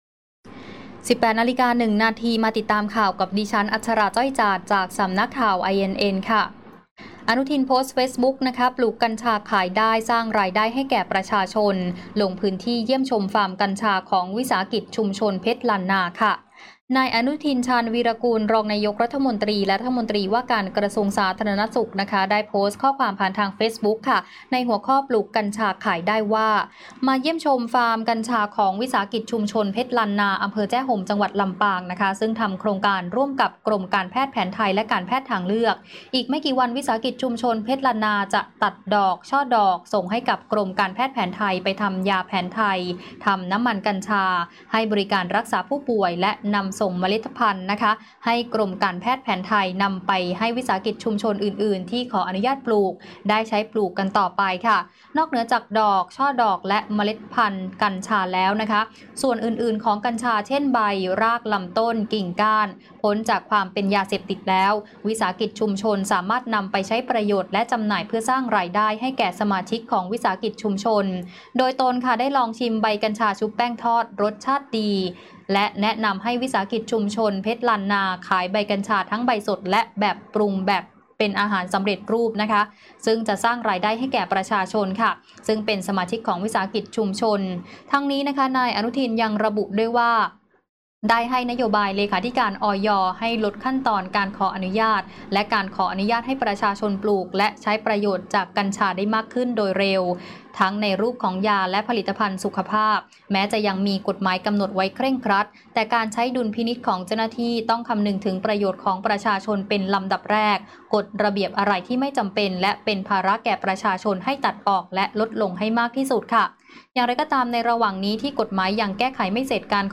คลิปข่าวต้นชั่วโมง
ข่าวต้นชั่วโมง 18.00 น.